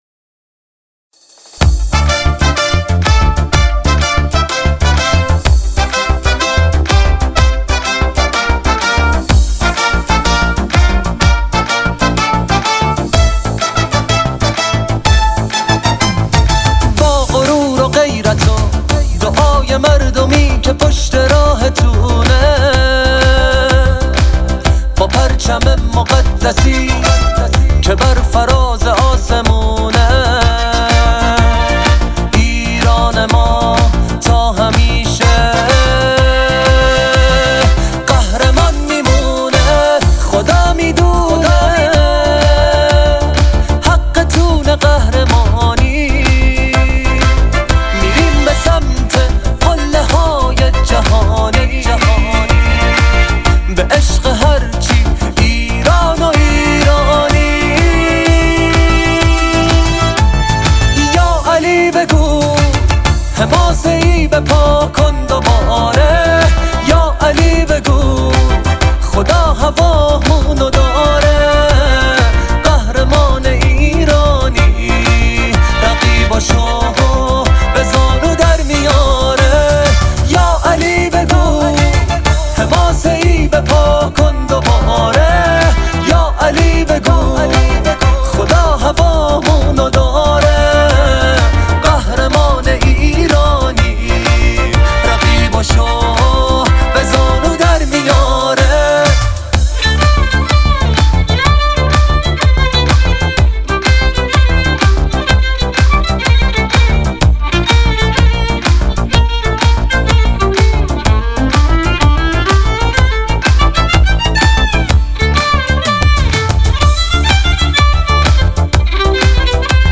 نوازنده ویولن
نوازنده ترومپت
نوازنده ترومبن
گیتار اسپانیش